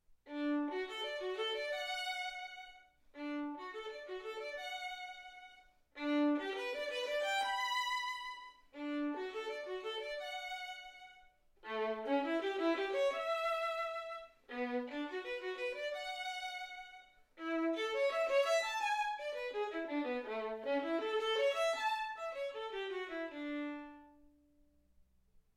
Hegedű etűdök Kategóriák Klasszikus zene Felvétel hossza 00:26 Felvétel dátuma 2025. december 8.